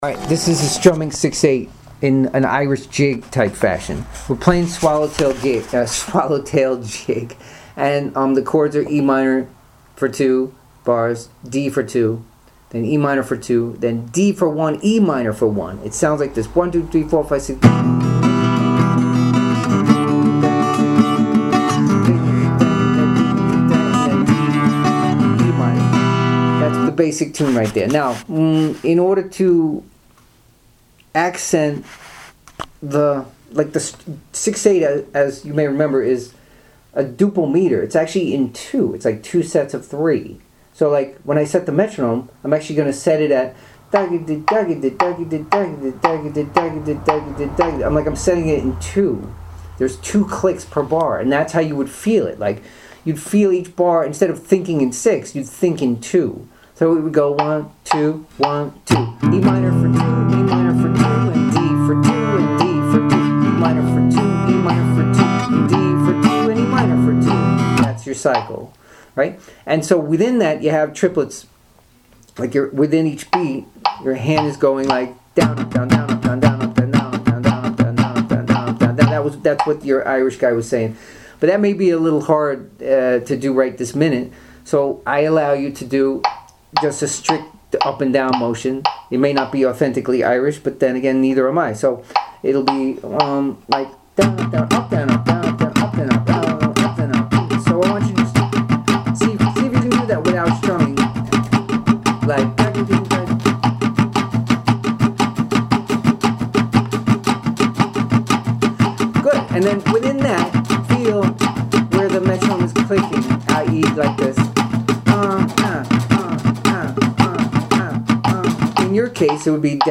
6-8-irish-jig-strumming.mp3